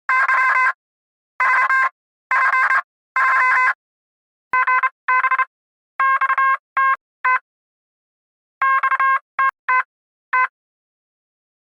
Free UI/UX sound effect: Morse Beeps.
Morse Beeps
yt_-advMFChcCQ_morse_beeps.mp3